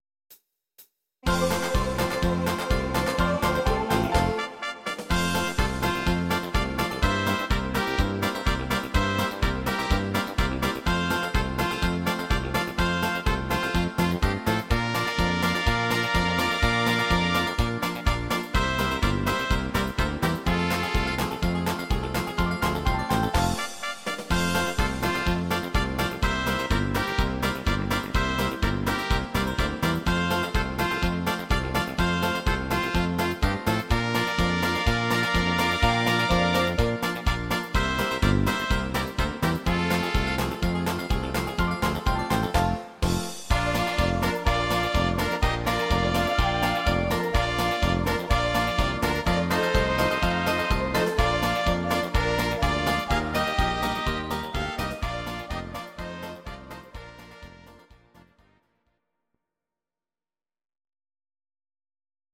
Audio Recordings based on Midi-files
Oldies, German, 1960s